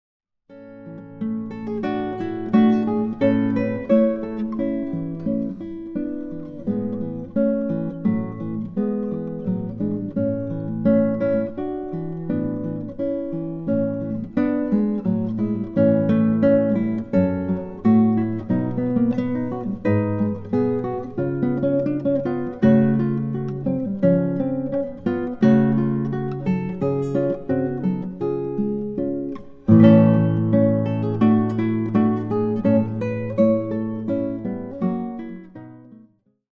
cedar/Brazilian guitar
deep, rich timbre